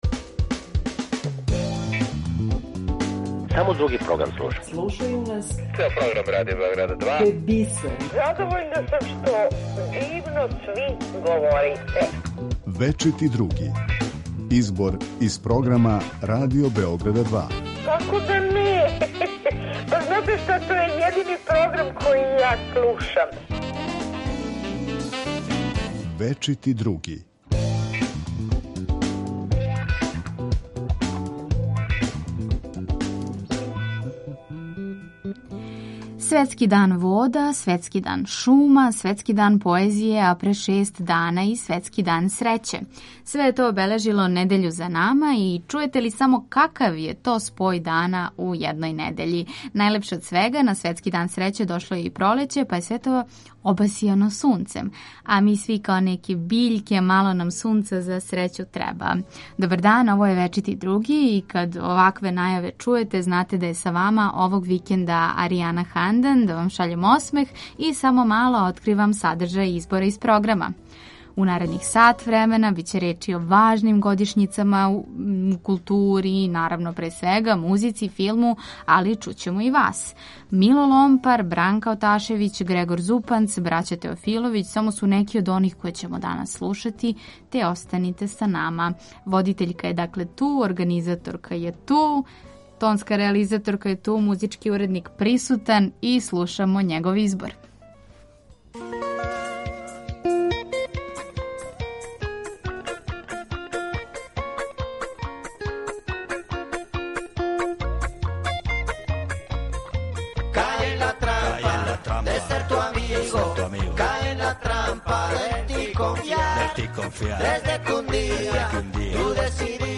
Чућемо и део репортаже о једном алтернативном културном центру, али слушаћемо и вас. Поред тога што издвајамо делове из прошлонедељног програма, најавићемо вече Поезија уживо и неке од емисија које ћете чути на Радио Београду 2.